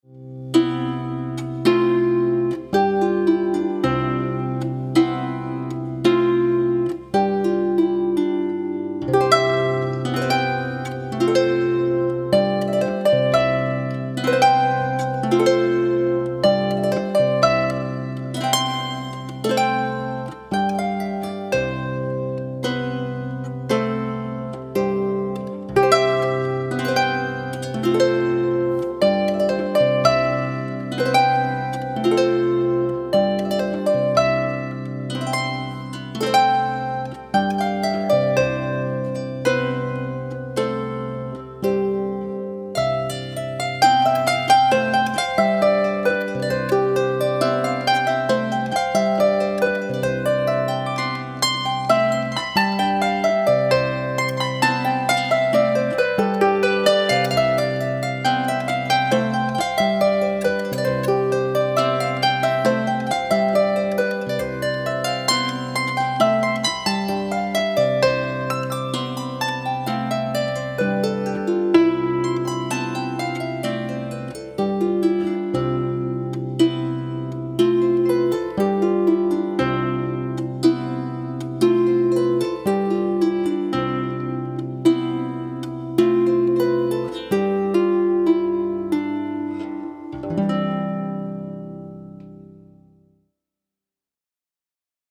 String Musicians
He is an absolute master of the Harp and Clarsach (small Scottish/Irish Harp),